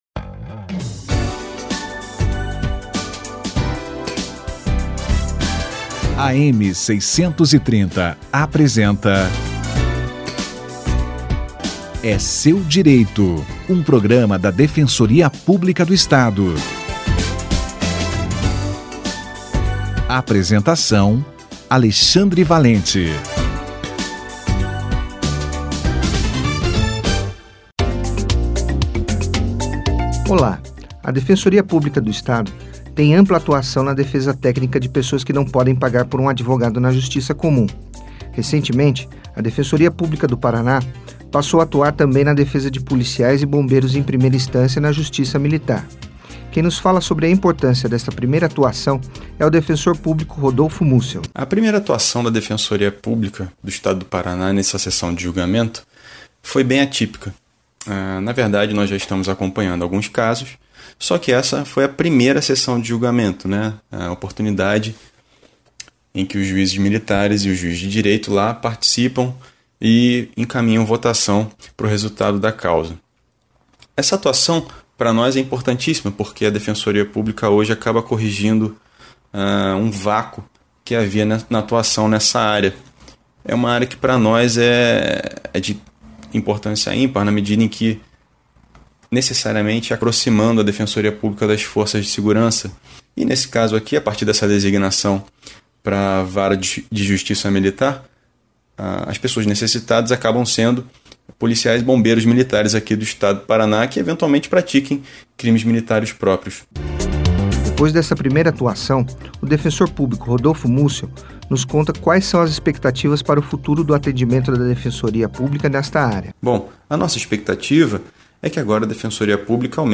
Defensoria Pública na Justiça Militar - Entrevista